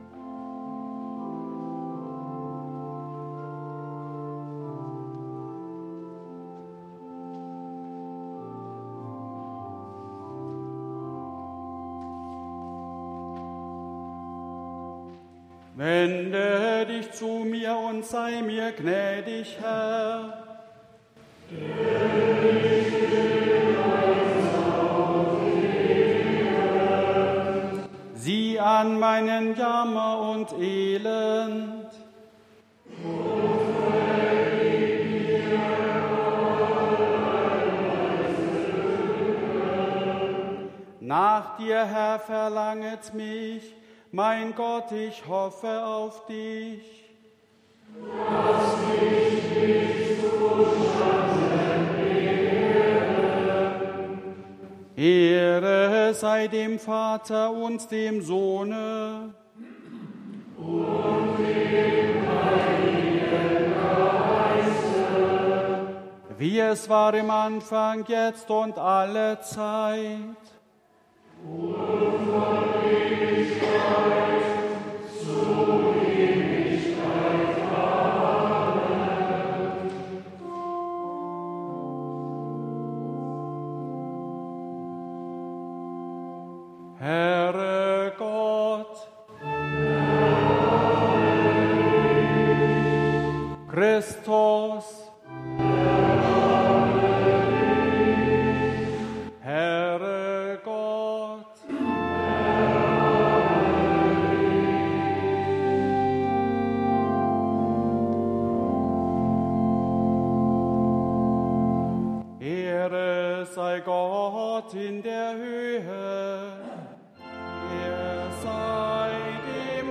3. Eingangsliturgie Ev.-Luth.
Audiomitschnitt unseres Gottesdienstes am 3.Sonntag nach Trinitatis 2024